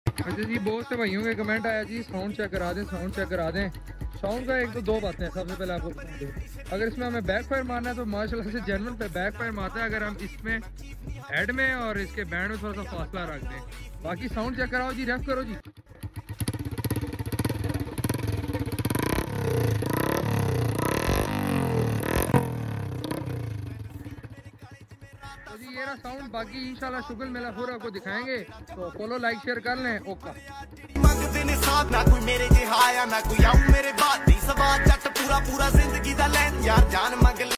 Honda 125 straight pipe